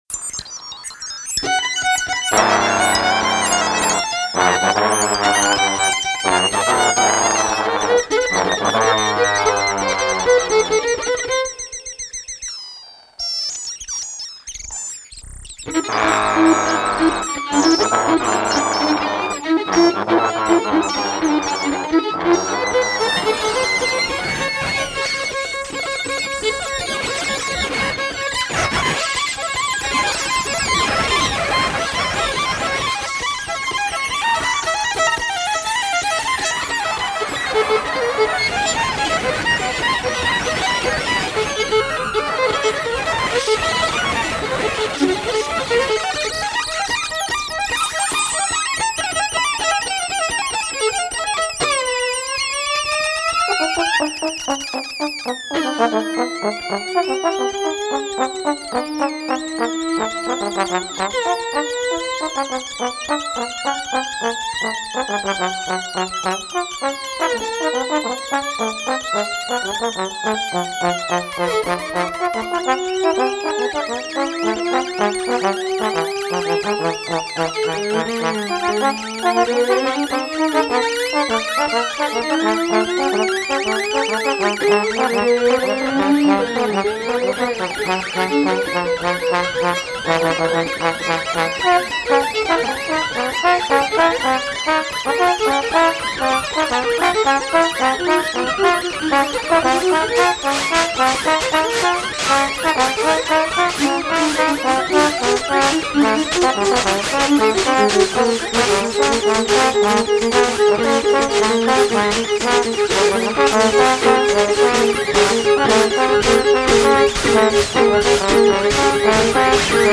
improvised music